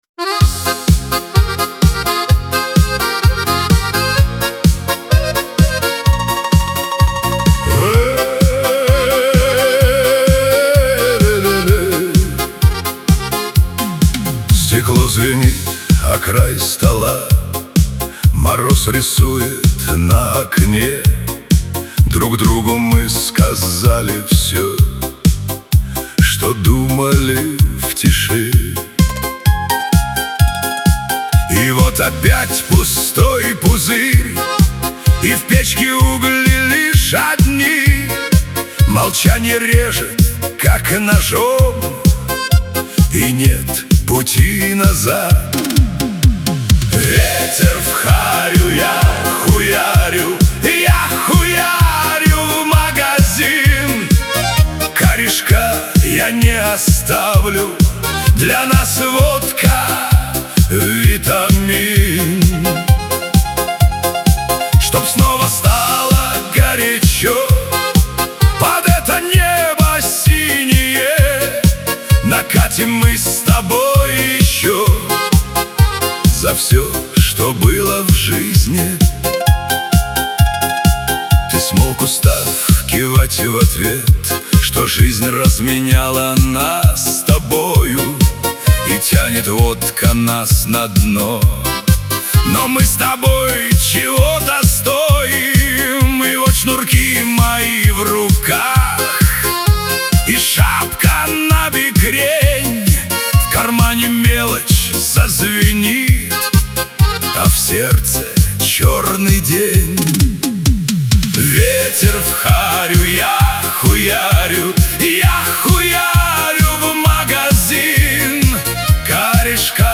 дуэт
Веселая музыка , Шансон